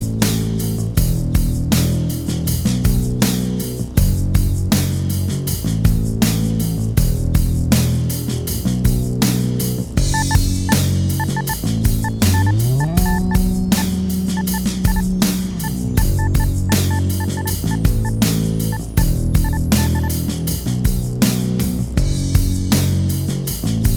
Minus Lead And Solo Indie / Alternative 6:39 Buy £1.50